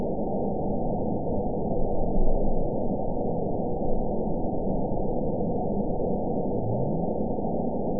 event 911309 date 02/22/22 time 03:50:17 GMT (3 years, 3 months ago) score 9.29 location TSS-AB01 detected by nrw target species NRW annotations +NRW Spectrogram: Frequency (kHz) vs. Time (s) audio not available .wav